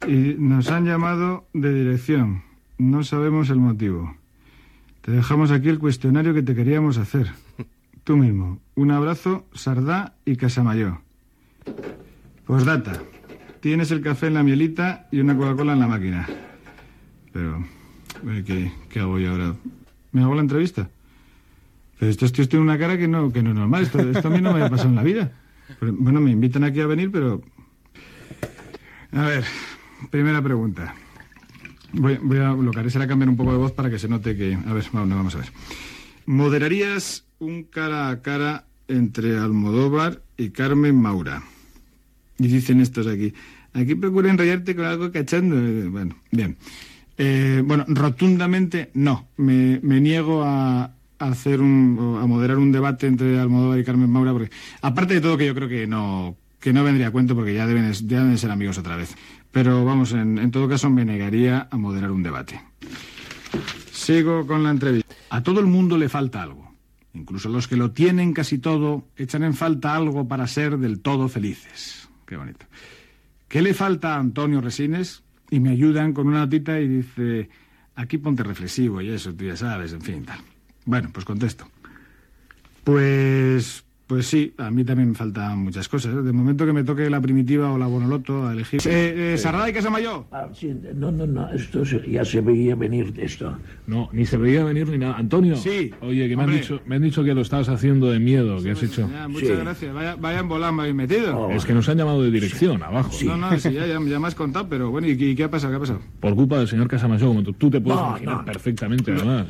Fragment d'una auto entrevista a l'actor Antonio Resines
Entreteniment